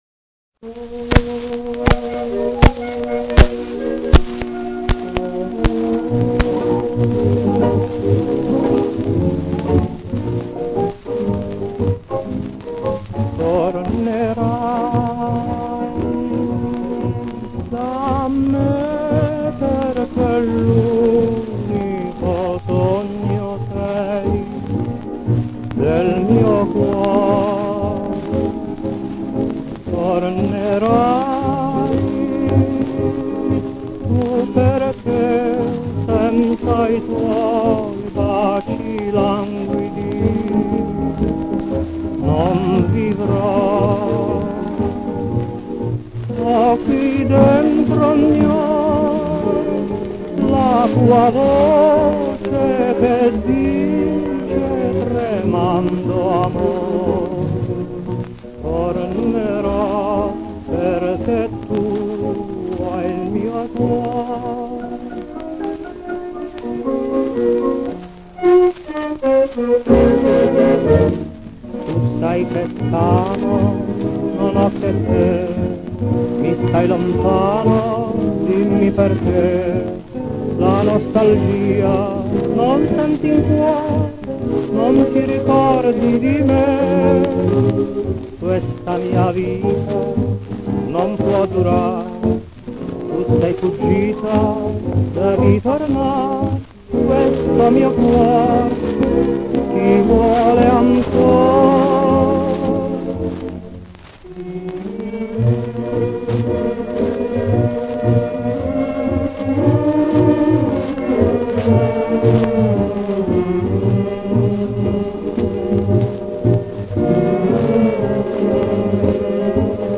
con la voce del tenore